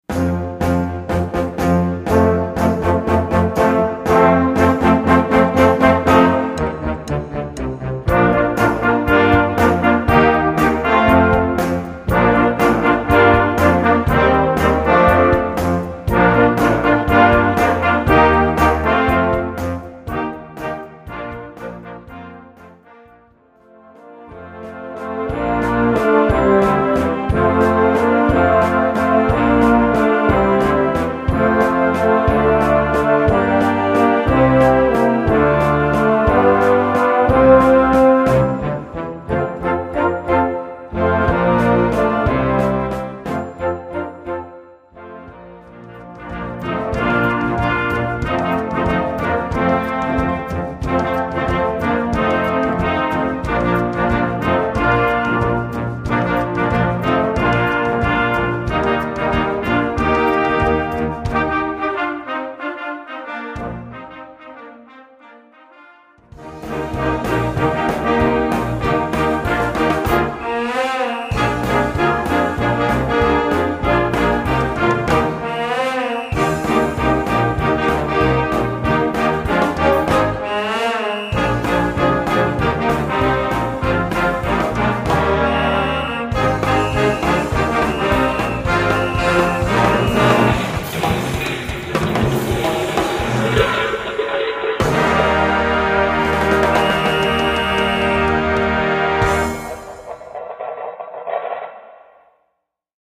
Concert Band ou Harmonie ou Fanfare